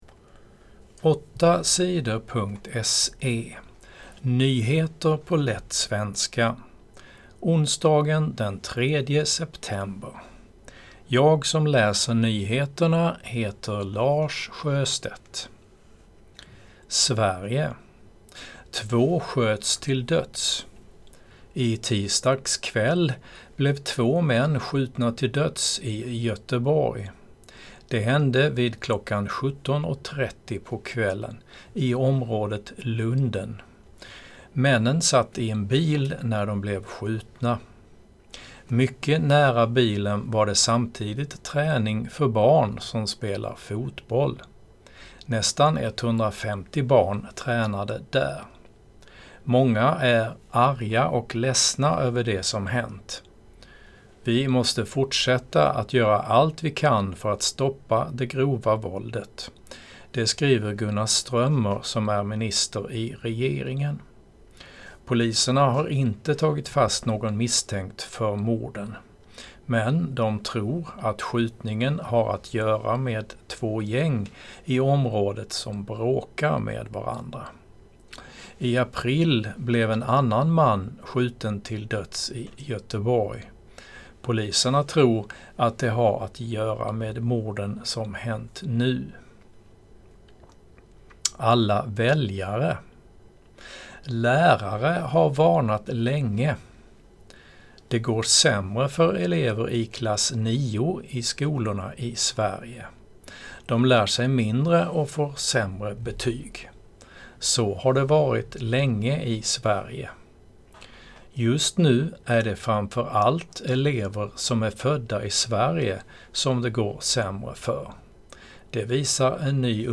Nyheter på lätt svenska den 3 september